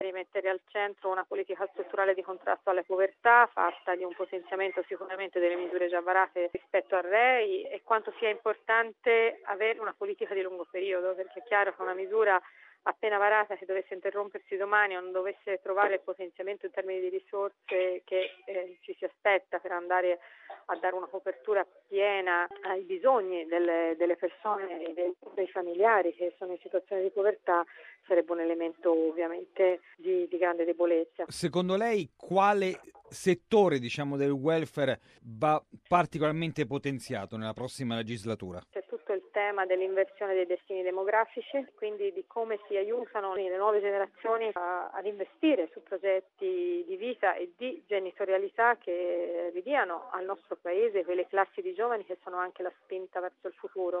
Povertà e nuove generazioni tra quelli citati dalla portavoce. L’intervista è andata in onda venerdì 2 marzo.